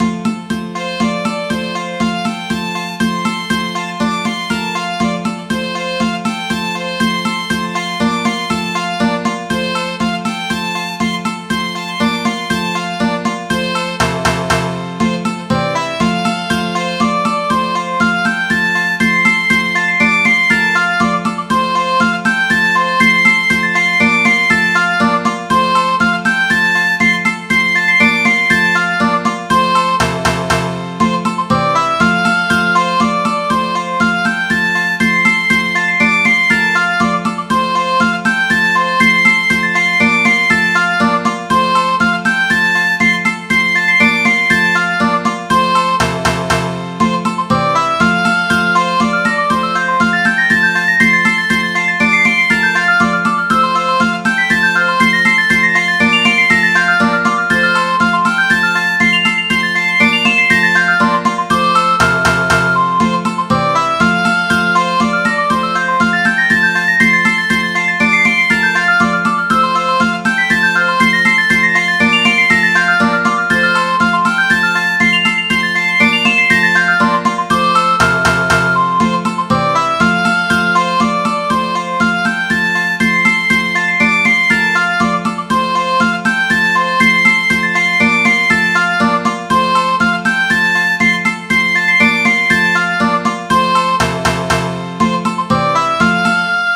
This is a sanitized version of a bawdy Irish traditional tune.
reilly.mid.ogg